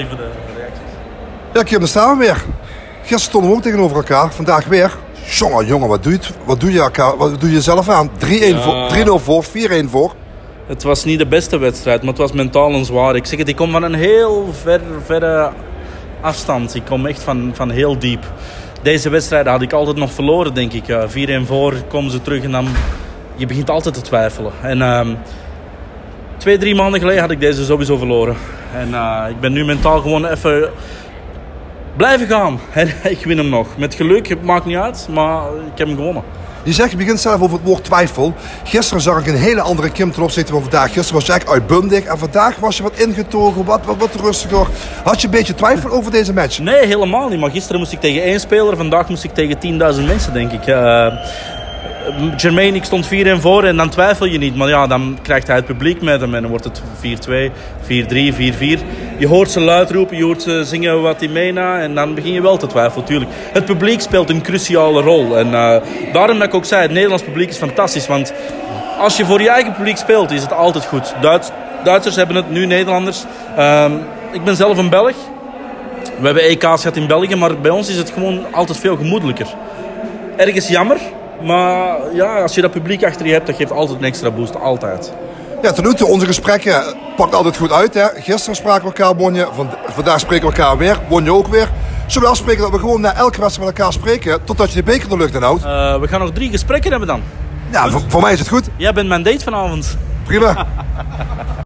Audio interview met Kim Huybrechts na een enerverende wedstrijd tegen Jermaine Wattimena, Wij hebben een date.